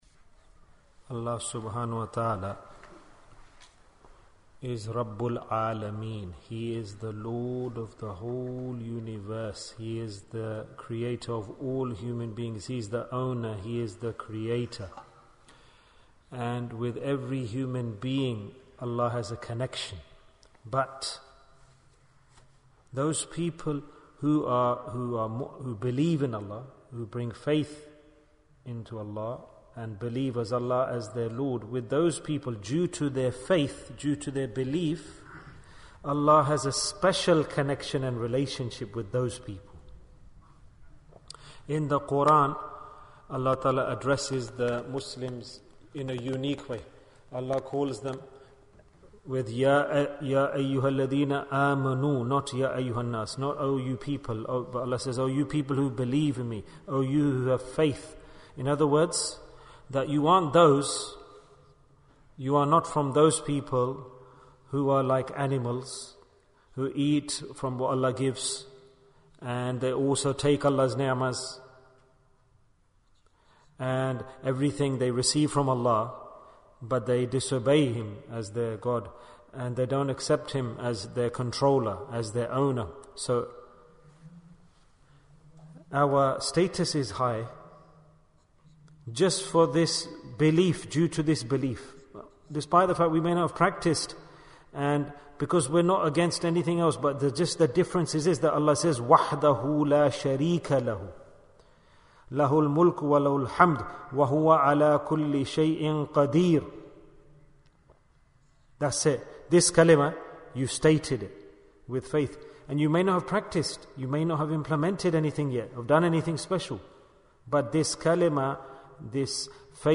The Path of Forgiveness Bayan, 46 minutes19th April, 2023